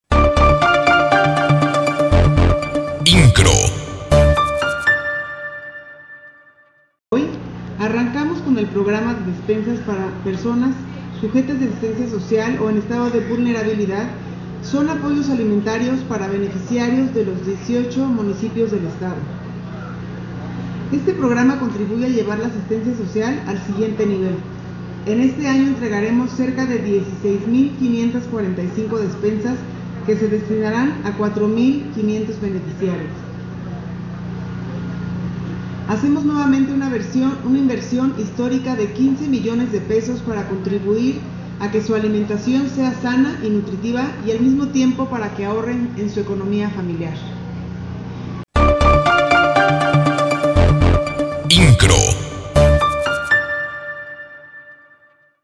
Car Herrera de Kuri, presidenta del Patronato del Sistema Estatal DIF, encabezó el arranque del Programa de Despensas para Personas Sujetas de Asistencia Social o en estado de Vulnerabilidad 2026, en la localidad Campo Santo Viejo, del municipio de Pinal de Amoles.